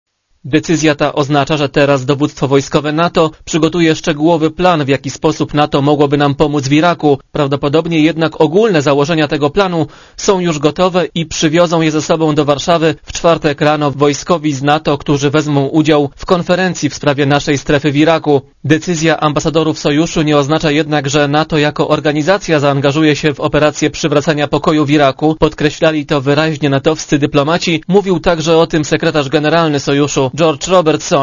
Co to oznacza? - korespondecja z Brukseli